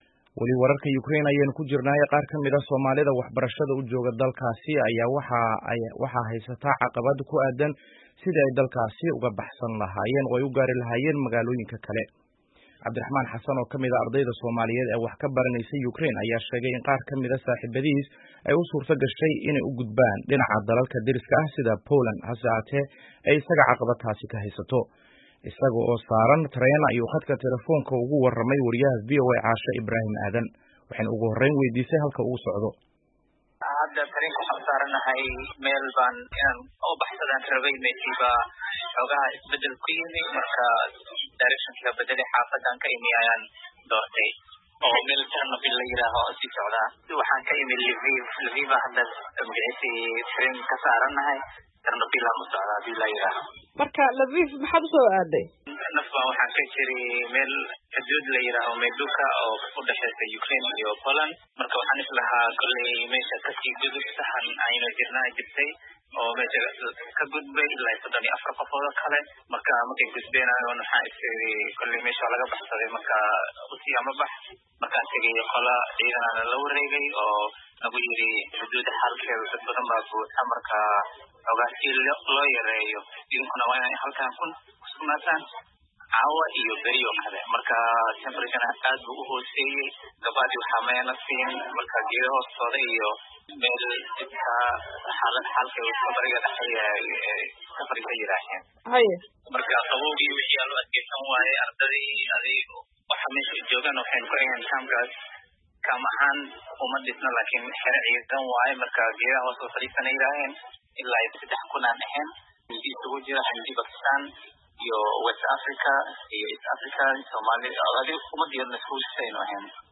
Wareysi arday Soomaali ah oo ka mid ah dadka ku xanniban Ukraine